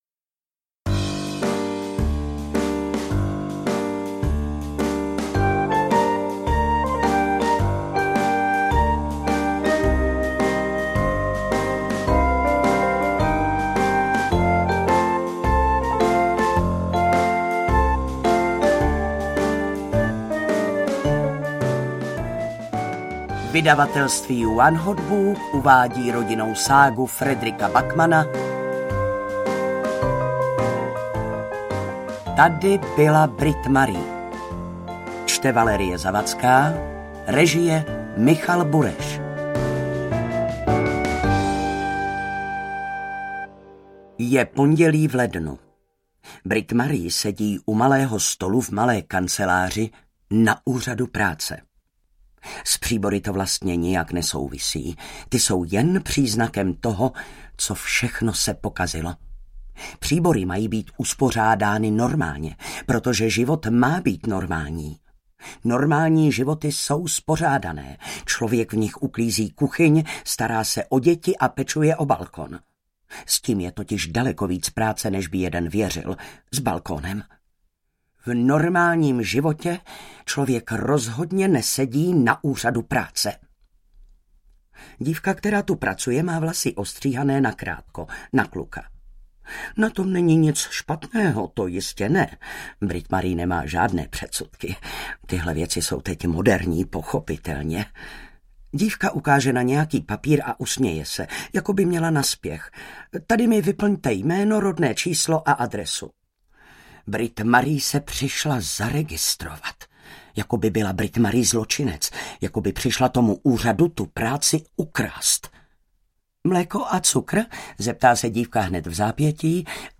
Tady byla Britt-Marie audiokniha
Ukázka z knihy
• InterpretValérie Zawadská